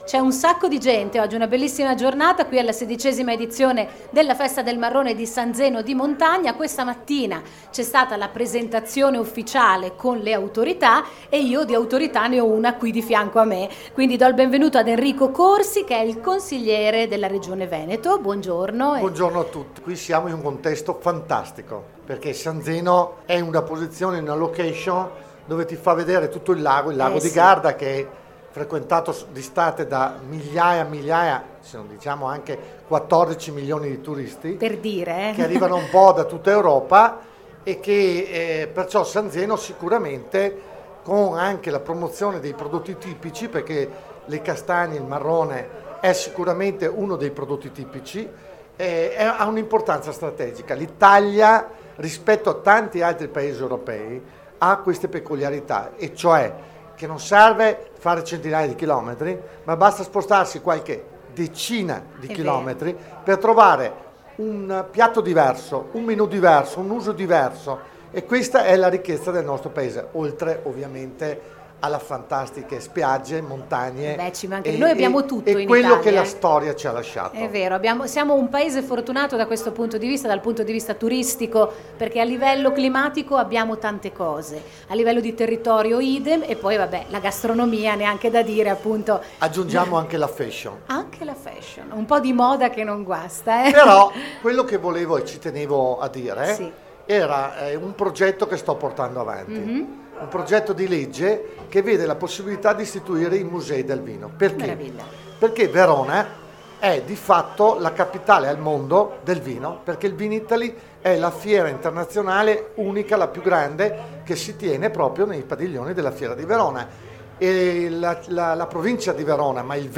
In collegamento dallo studio mobile
Enrico Corsi, Consigliere di Regione Veneto